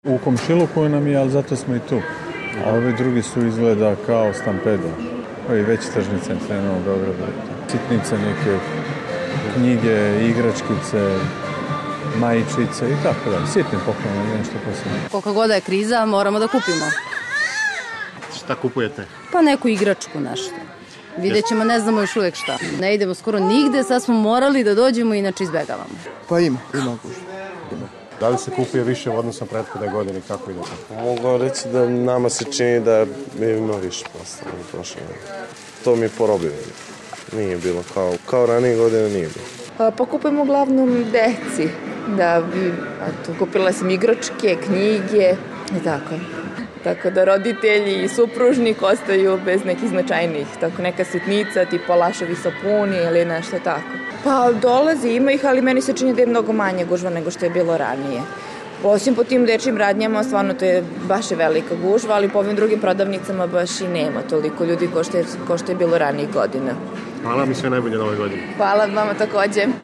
Anketa: Šoping u Ziri